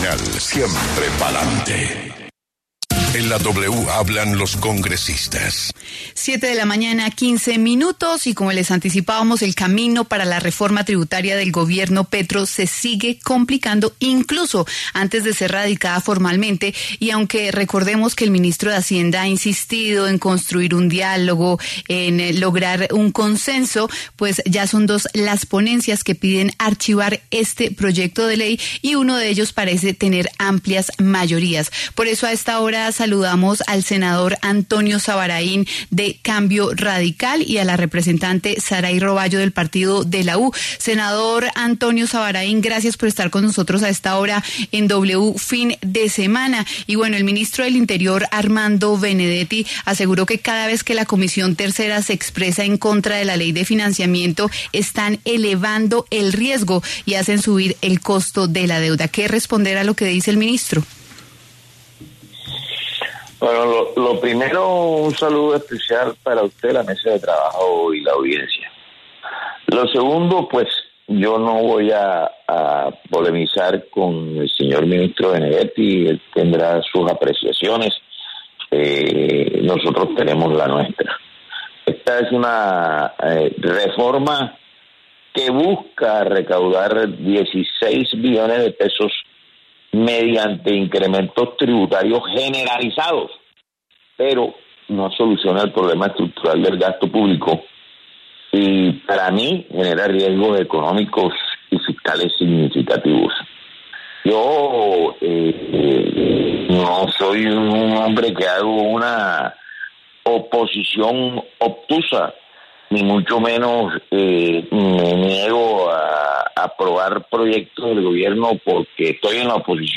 W Fin De Semana conversón con los congresistas Antonio Zabaraín y Saray Robayo, quienes aseguraron que la ley de financiamiento del Gobierno Nacional no sería beneficiosa para los colombianos porque es más gravosa.